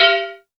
SOUTHSIDE_percussion_small_metallic.wav